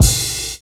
2509R CYM.wav